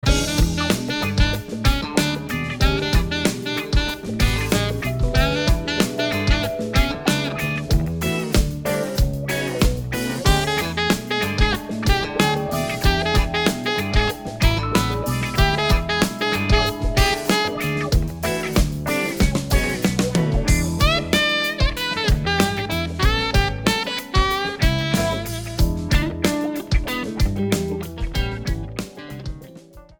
The funk arrangement of